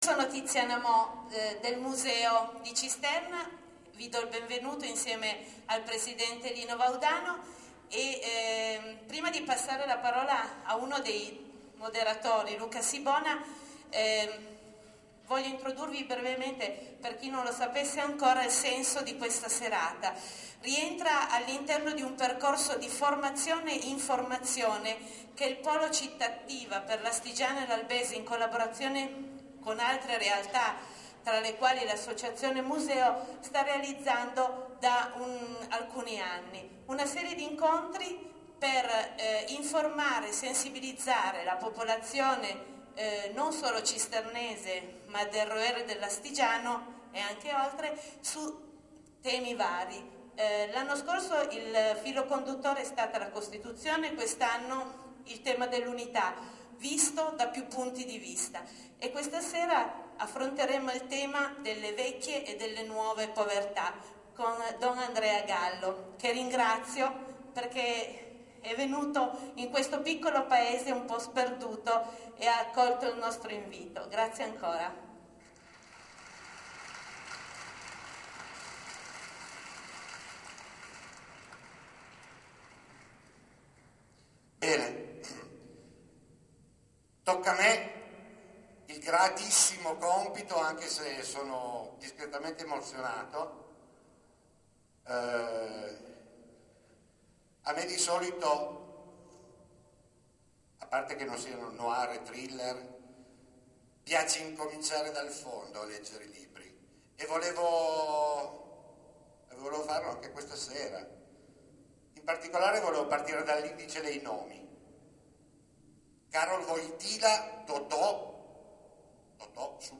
Castello di Cisterna d’Asti gremito all’inverosimile per l’incontro con don Andrea Gallo, Comunità di San Benedetto al Porto di Genova, che si è tenuto venerdì 15 aprile 2011 alle 21,00 organizzato dal Polo Cittattiva per l’Astigiano e l’Albese - di cui la Direzione Didattica di S. Damiano d’Asti è capofila – in collaborazione con il Museo Arti e Mestieri di un Tempo di Cisterna d’Asti.